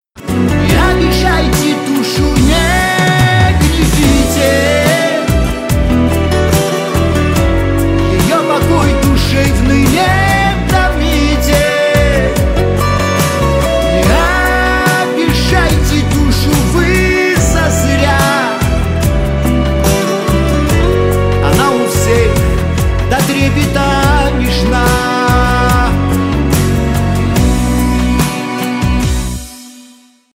Шансон
спокойные